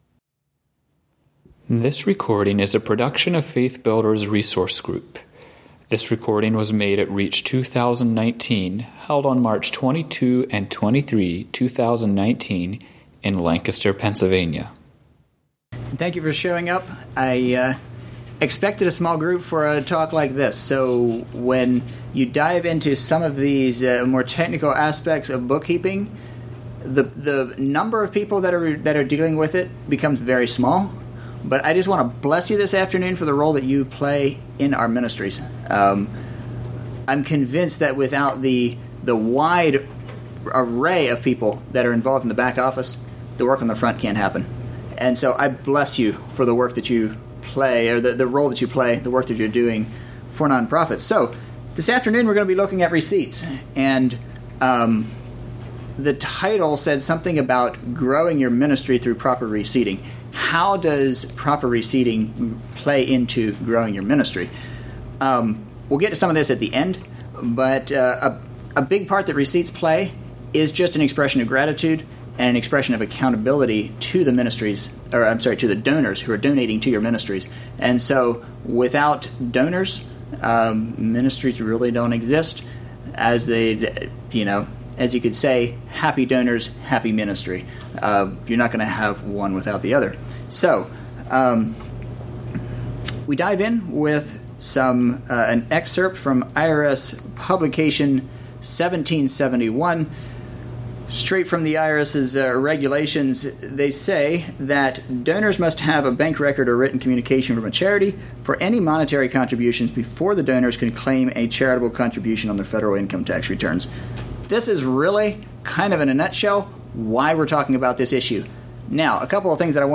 Home » Lectures » “May I Have a Receipt?”